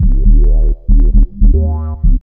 5008L B-LOOP.wav